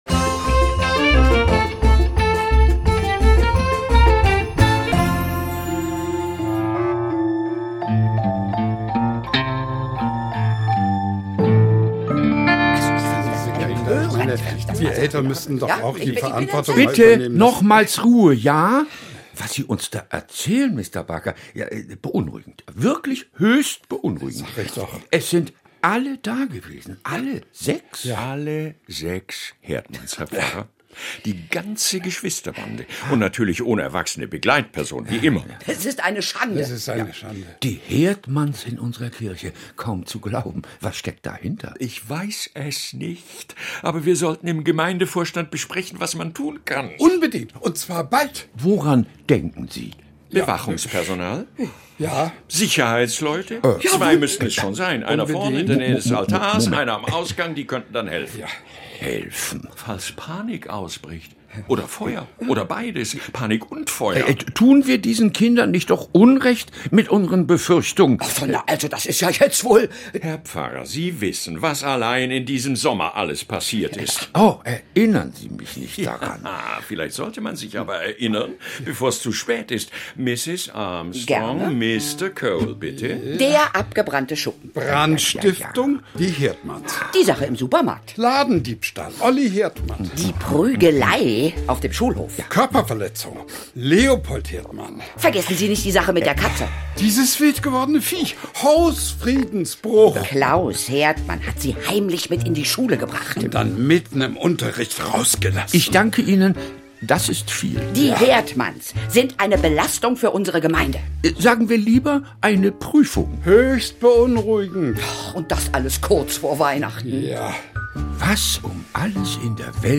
Kinderhörspiel: Hilfe, die Herdmanns kommen!